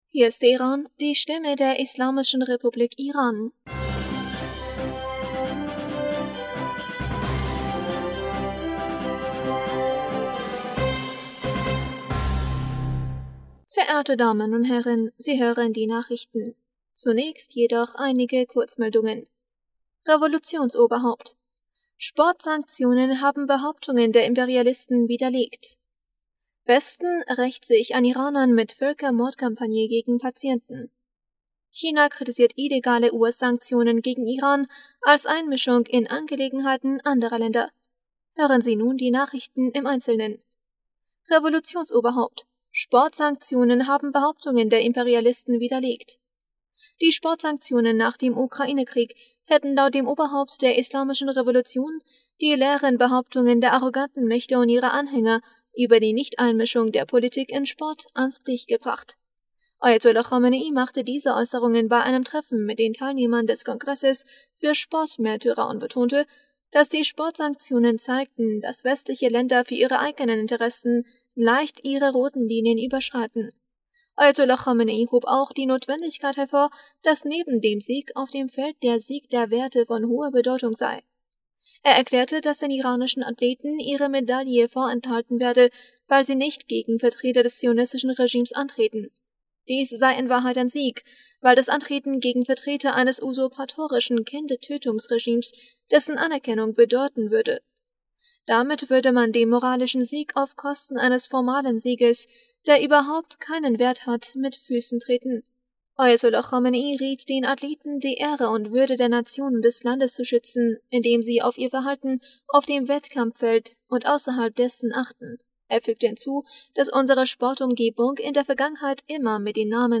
Nachrichten vom 10. Oktober 2022